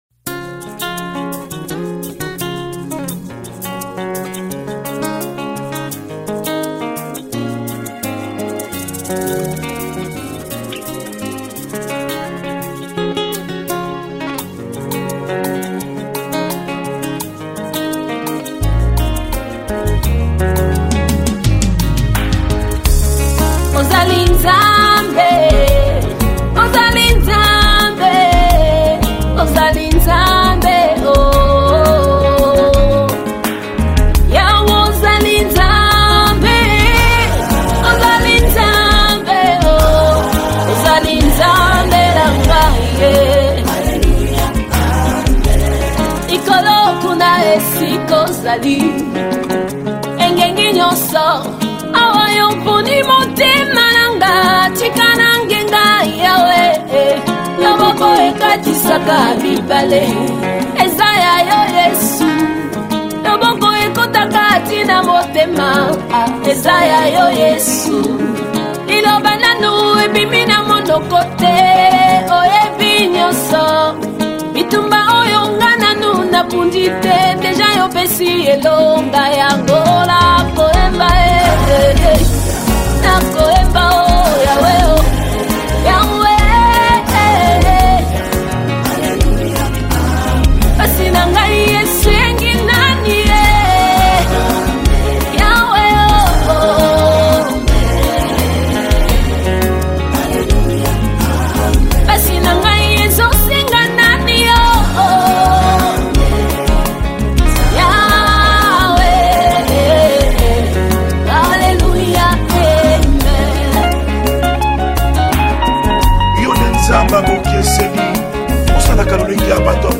Gospel 2017